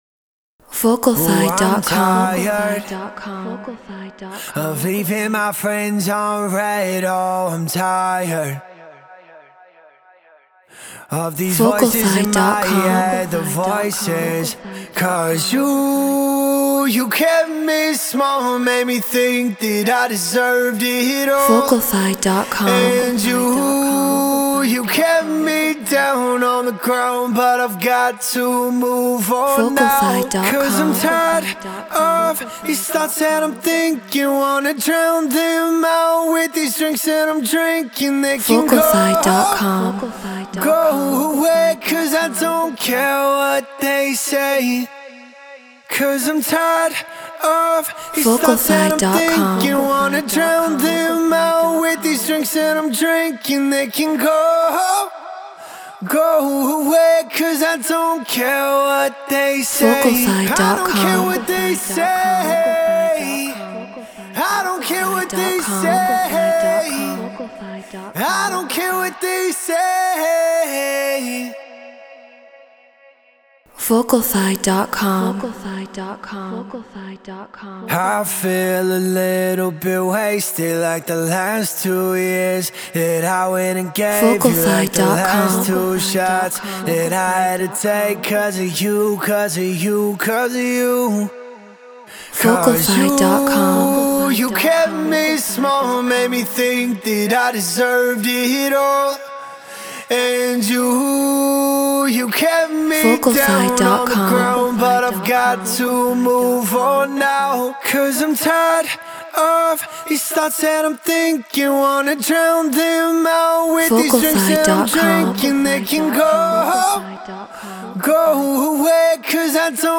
House 124 BPM Emaj
Neumann TLM 103 Focusrite Scarlett Ableton Live Treated Room